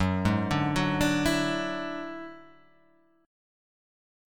F#7sus2#5 chord